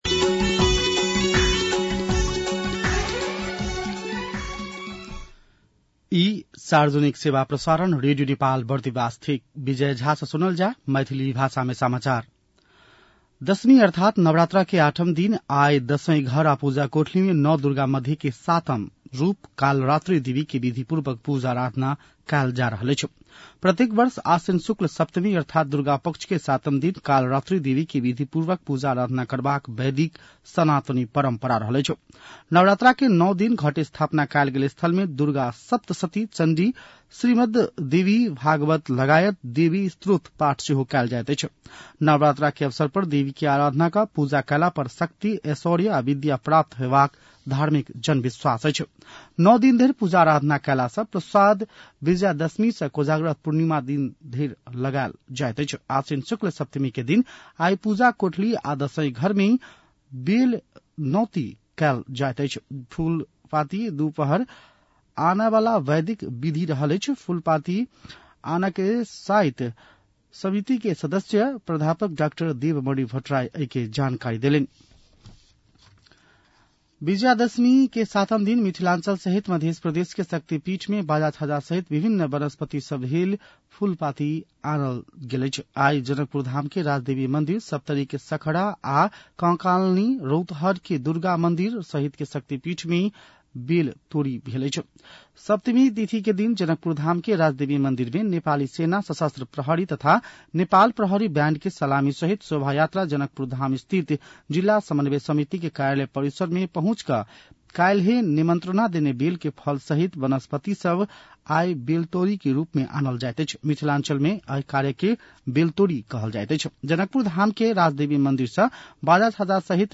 मैथिली भाषामा समाचार : १३ असोज , २०८२
MAITHALI-News-6-13.mp3